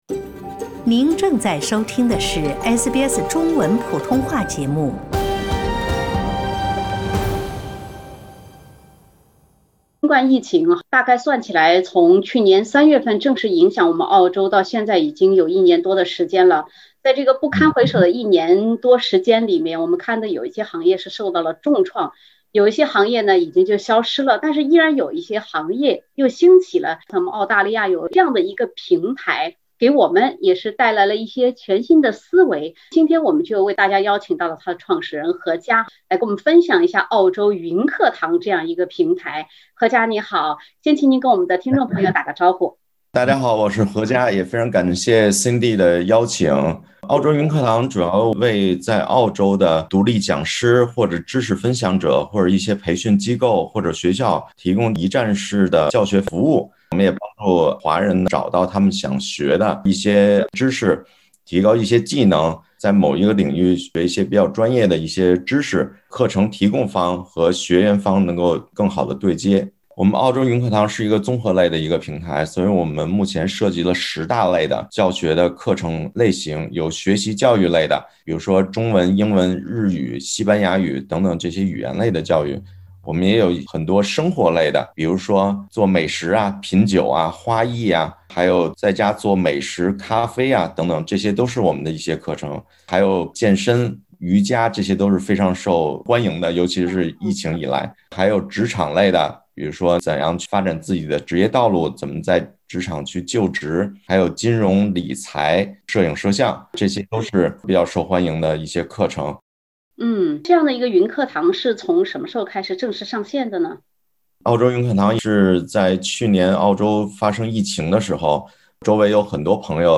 （点击封面图片，收听完整采访） 澳洲一年多的疫情期，让一些行业衰败凋敝，也在一些领域冒出了崭新的机会。